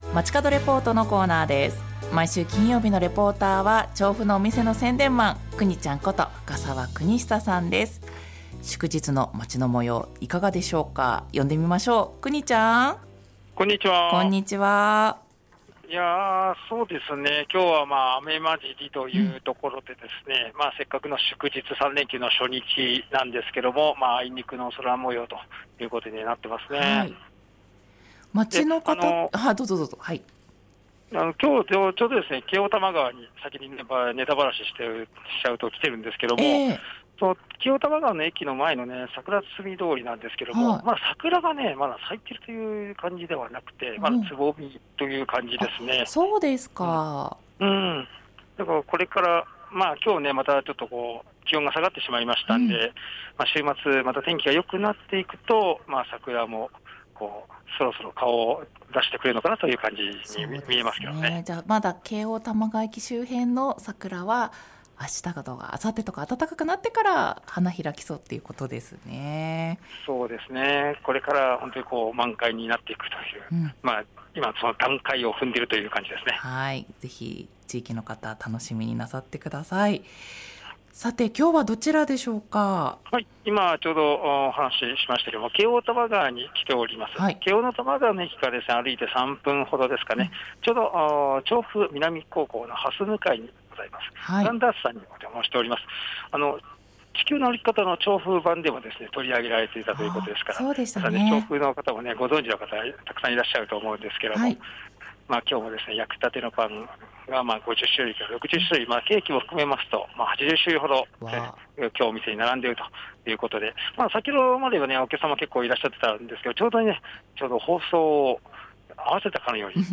午後のカフェテラス 街角レポート